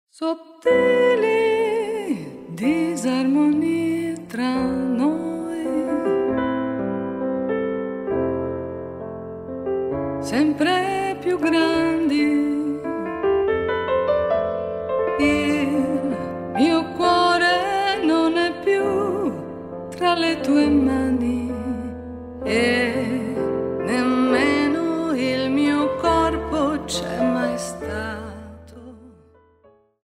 for female voice and two pianists
a Steinway mod.D 274 piano.